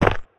step-4.wav